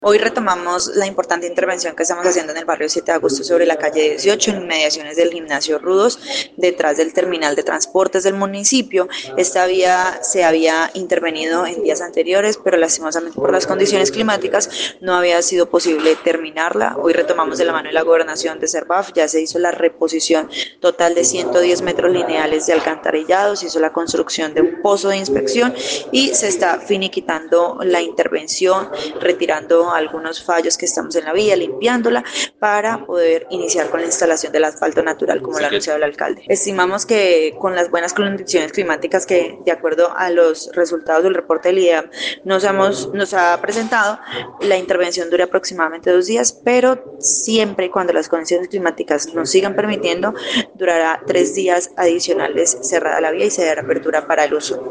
Anggie Méndez, secretaria encargada de Obras Públicas en el municipio, explicó que tras sobre llevar temas climáticos y algunas condiciones especiales de humedad en estos lugares, se retomaron los trabajos con el ánimo de terminar dichas obras durante la presente semana.